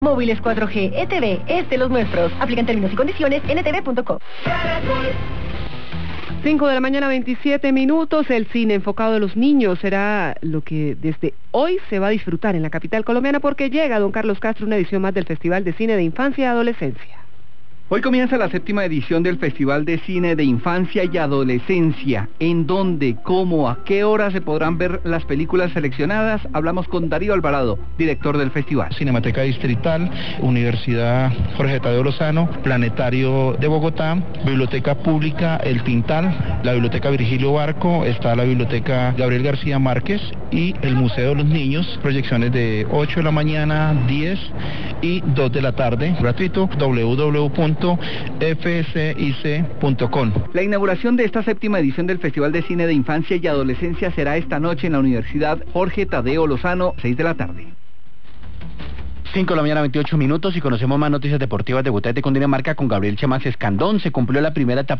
Por su parte, Caracol Radio, en su emisión de la mañana, destacó el Festival de cine enfocado en los niños.